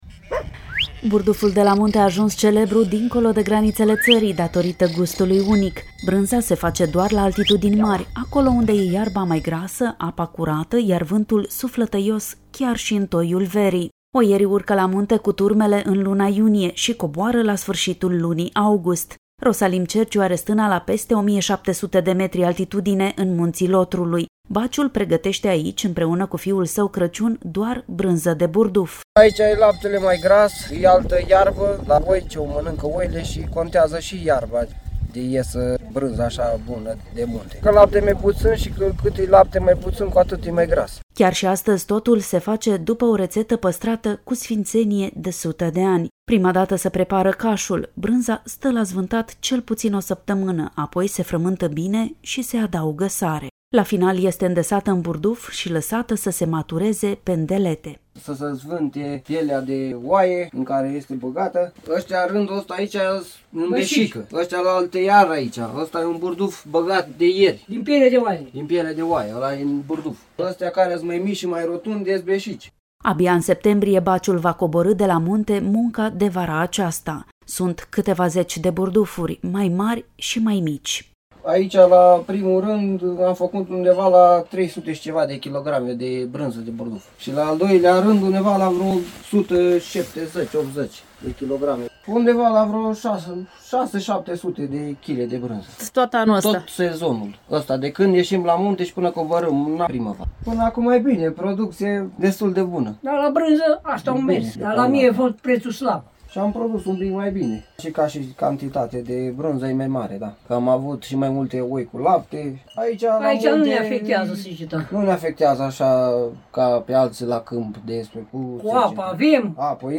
Reportaj: Cum este făcută brânza de burduf la o stână din Munții Lotrului.
Mergem în județul Sibiu – mai exact – la o stână din Munții Lotrului – la peste 1.700 de metri altitudine.